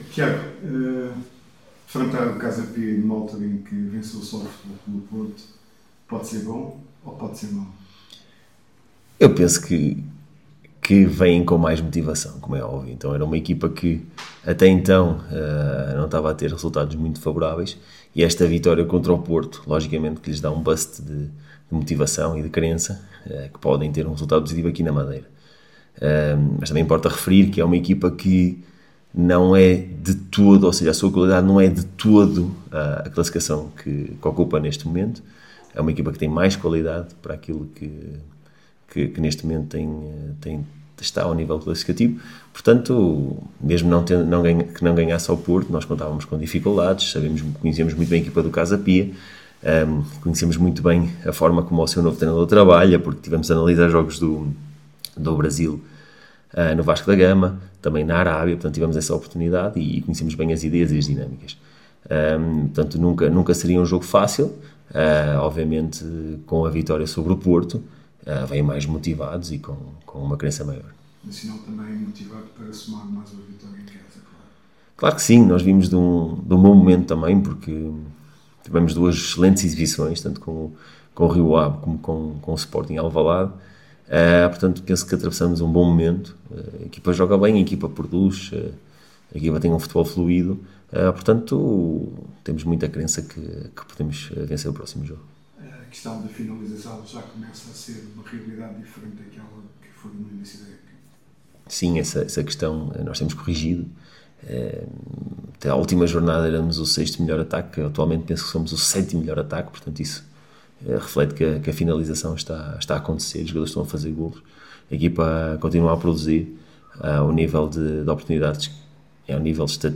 Na conferência de imprensa de antevisão à partida